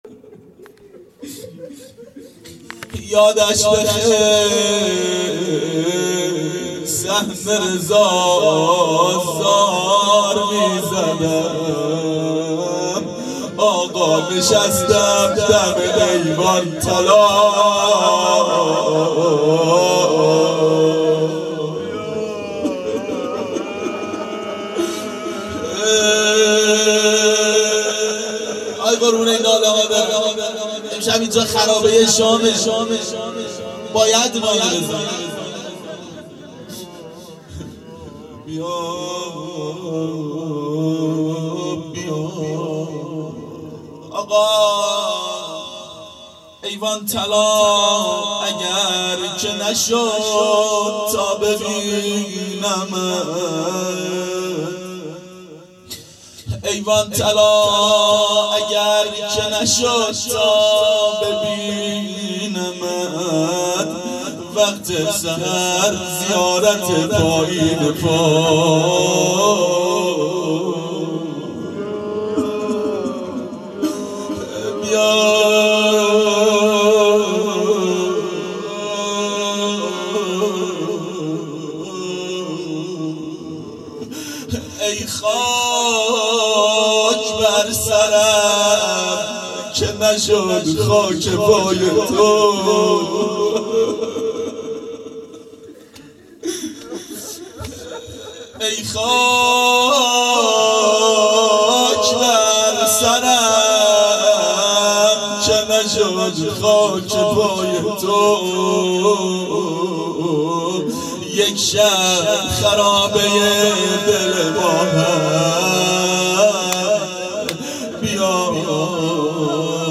شب سوم محرم 92 هیأت عاشقان اباالفضل علیه السلام منارجنبان
01-مناجات-با-امام-زمان-و-روضه-حضرت-رقیه.mp3